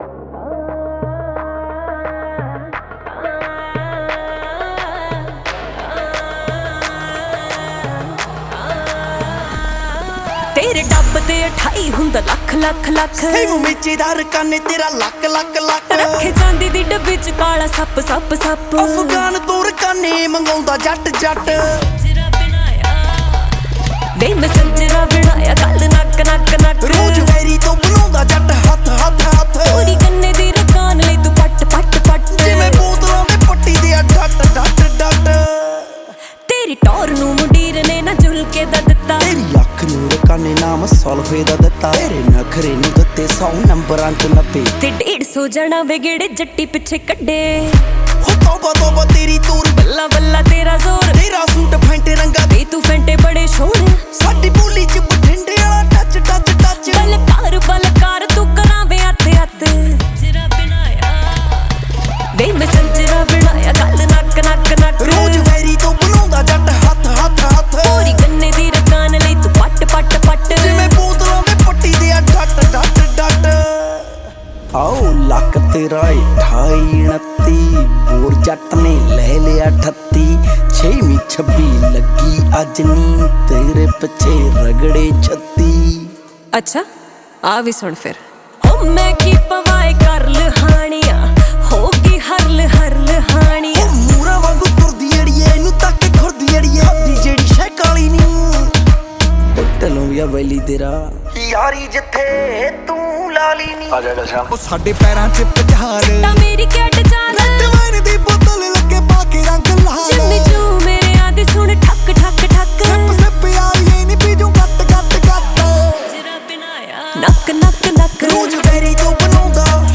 punjabi song
The beautiful song in sweet voice